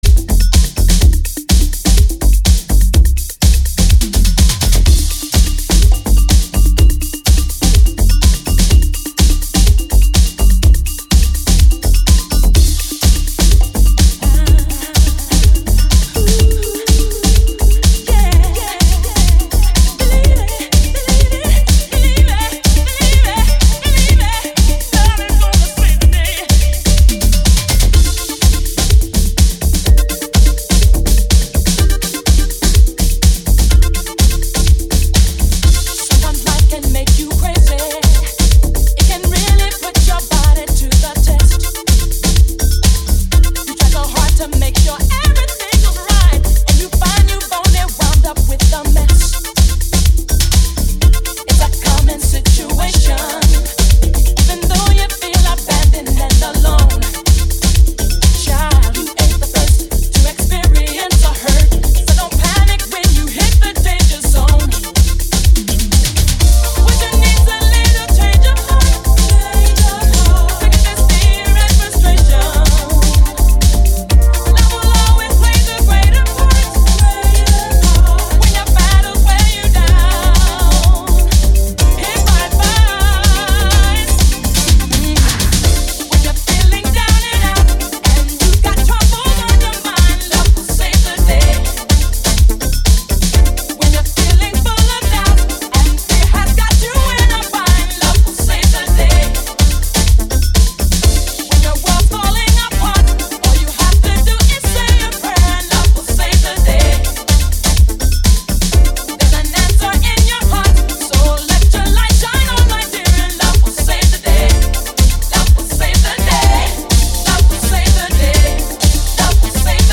Genre: Urban/Rhythmic , House , Spanish/Latin